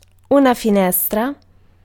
Ääntäminen
Vaihtoehtoiset kirjoitusmuodot (rikkinäinen englanti) winduh (vanhentunut) windore Synonyymit gap shop window ticket office box office ticket window Ääntäminen UK : IPA : [ˈwɪn.dəʊ] : IPA : /ˈwɪndoʊ/ US : IPA : [wɪn.doʊ]